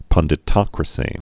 (pŭndĭ-tŏkrə-sē)